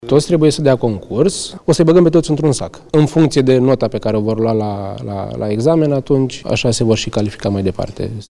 29ian-13-Ciprian-Serban-toti-intr-un-sac.mp3